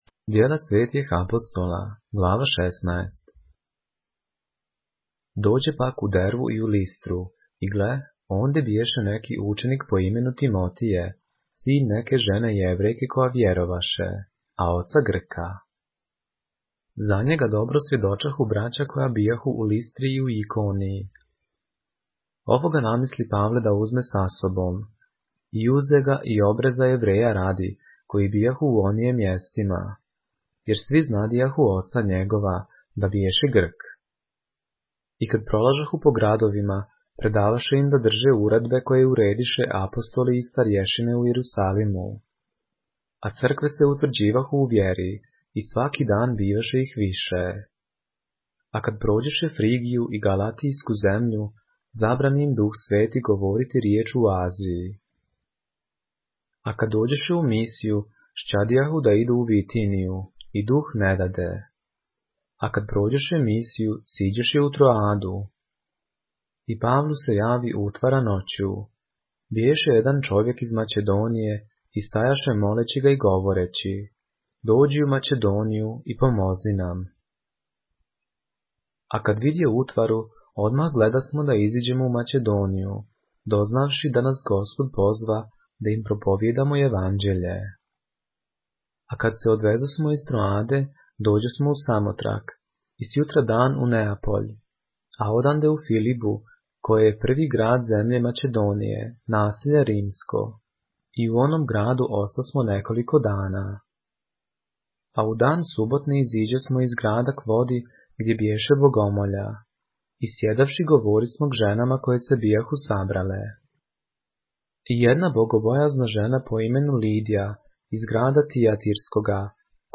поглавље српске Библије - са аудио нарације - Acts, chapter 16 of the Holy Bible in the Serbian language